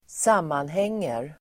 Ladda ner uttalet
Uttal: [²s'am:anheng:er]